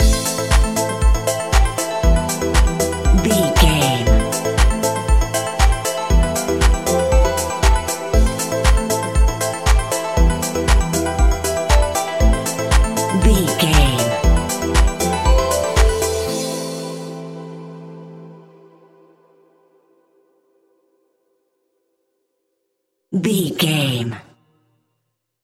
Aeolian/Minor
dark
futuristic
epic
groovy
drum machine
synthesiser
electric piano
house
electro house
synth leads
synth bass